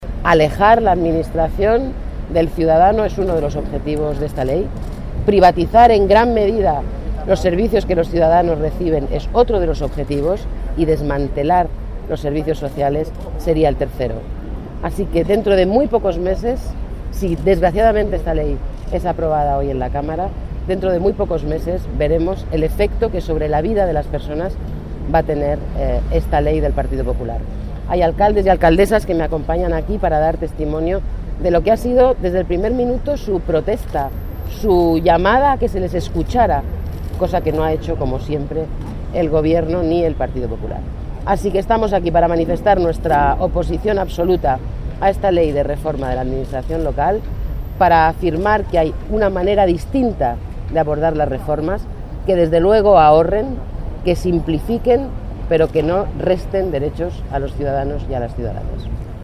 Declaraciones sobre la reforma de los ayuntamientos del gobierno que privatizará servicios sociales 17/10/13